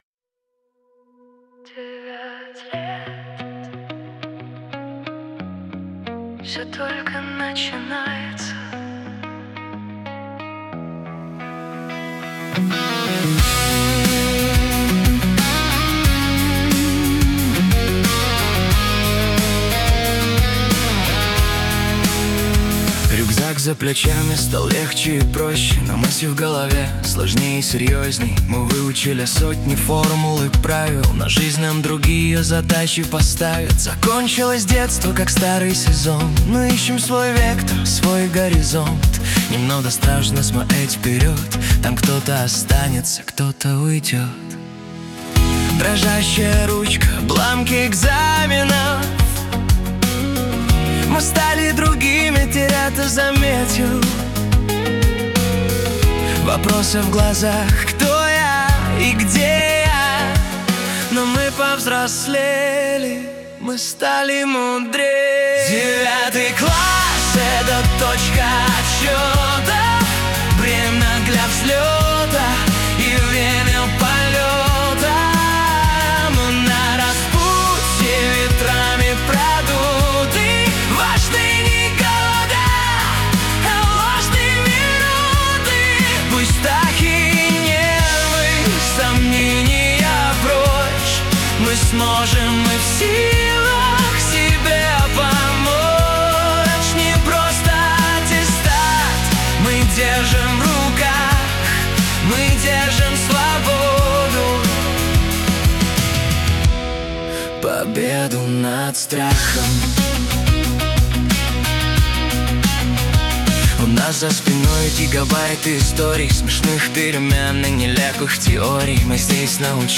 Трогательная песня. Светлая грусть и поддержка.
🌅 Вайб: Уют и Гитара